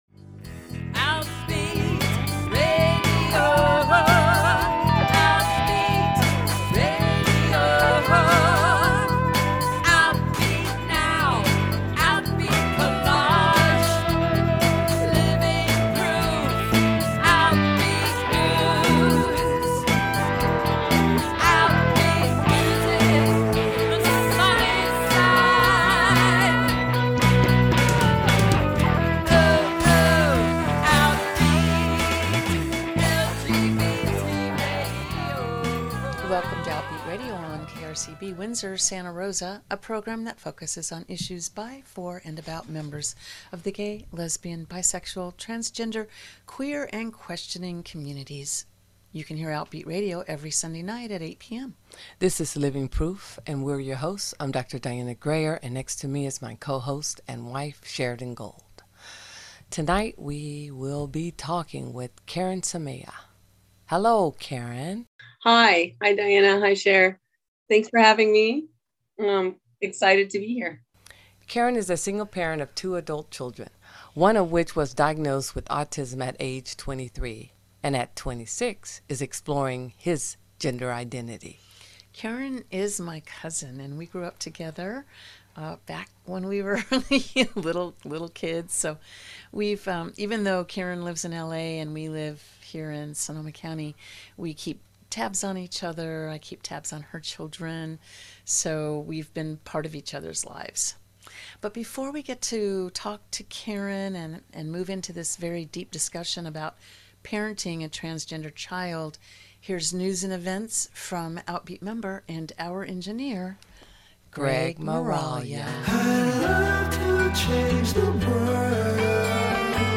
Great interview.